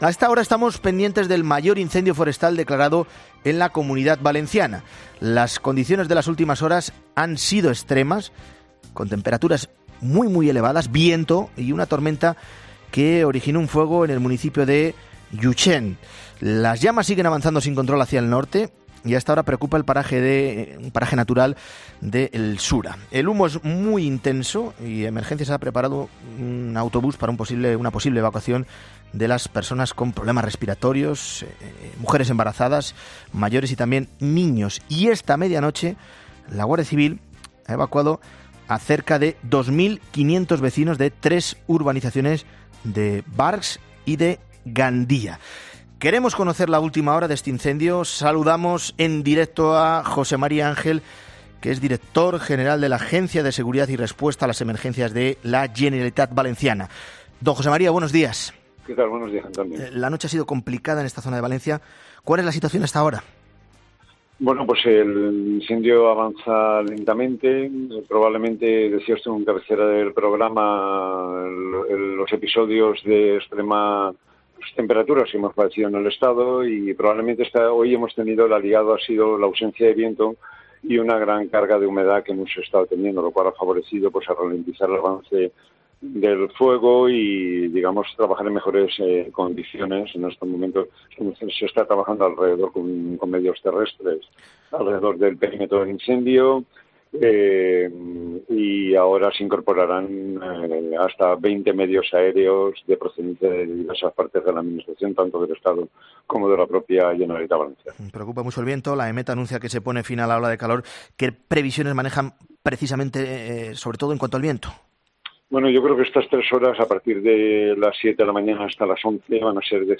Para conocer cómo se están desarrollando las actividades de emergencia y cuáles son las previsiones que esperan ha hablado en 'Herrera en COPE' José María Ángel, director general de la Agencia Valenciana de Seguridad y Respuesta a Emergencias.